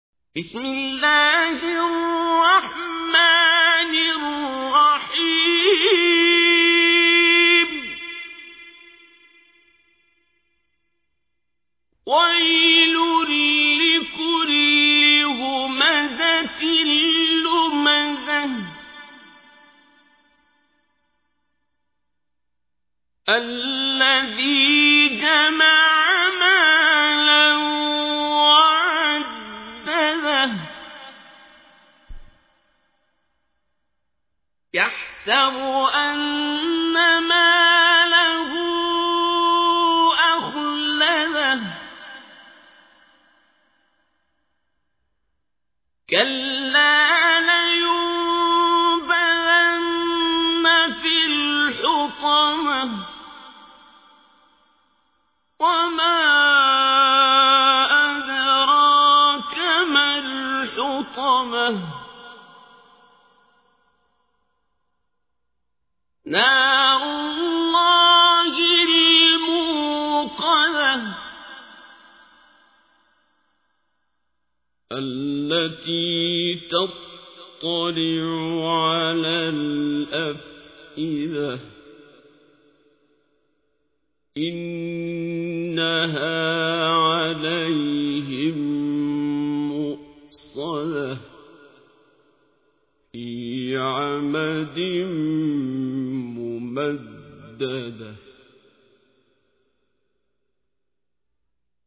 تحميل : 104. سورة الهمزة / القارئ عبد الباسط عبد الصمد / القرآن الكريم / موقع يا حسين